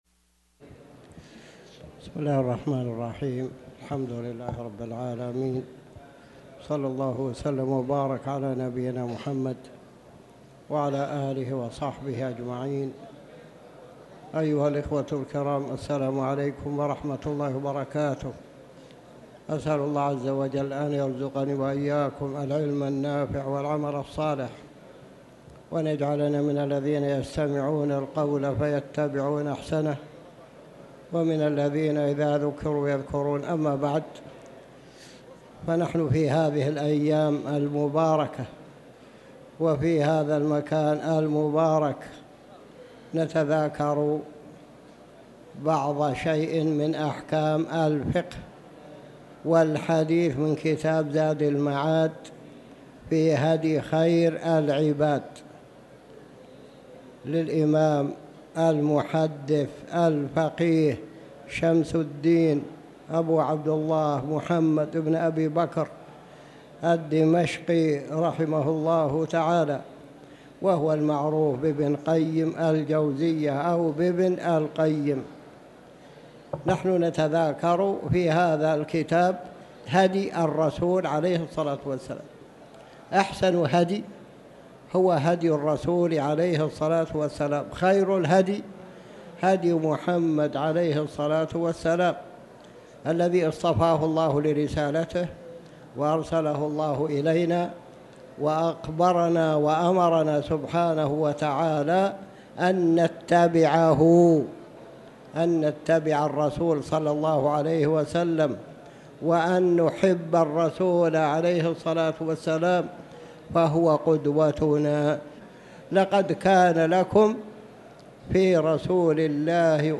تاريخ النشر ١٧ ذو الحجة ١٤٤٠ هـ المكان: المسجد الحرام الشيخ